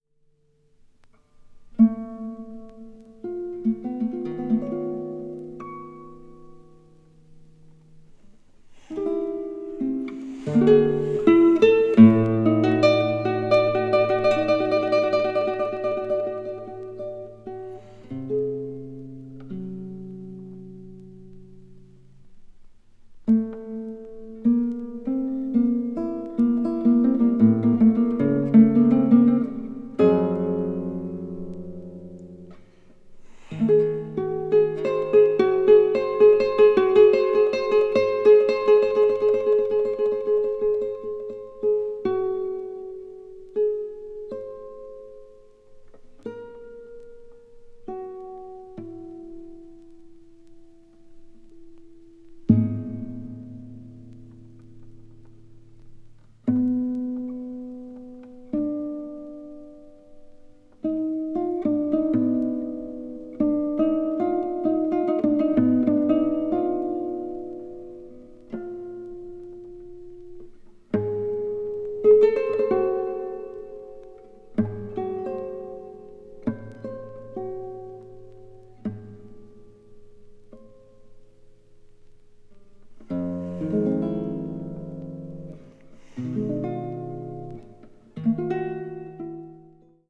20th century   chamber music   contemporary   post modern